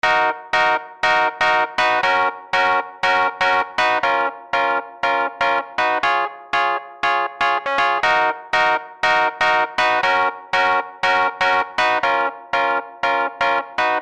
искать нужно среди звуков \organ/ в Lounge lizard куча похожих звуков, вот например пресет раздел 5 classic tracks : These Eyes Вложения lounge.mp3 lounge.mp3 547,5 KB · Просмотры: 323 Последнее редактирование: 18 Июл 2015